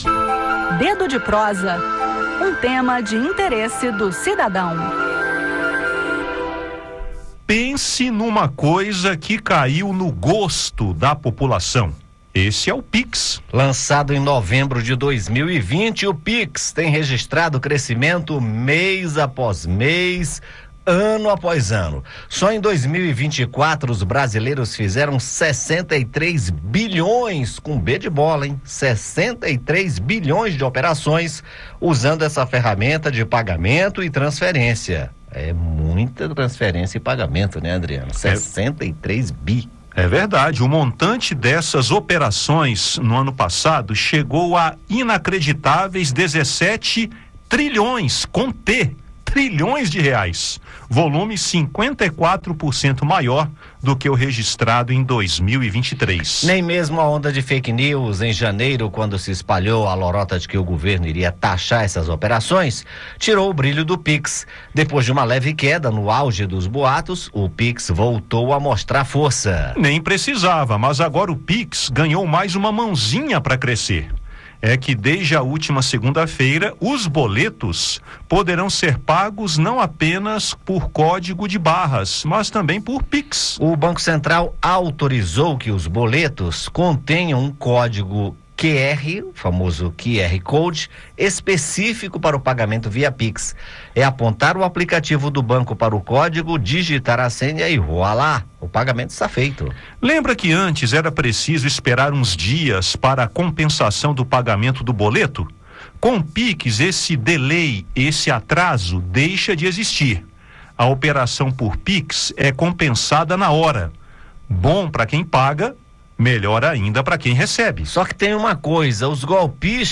E ela vem para facilitar ainda mais a vida de quem precisa fazer pagamentos de boletos. Ouça detalhes no bate-papo e saiba como não cair em golpes.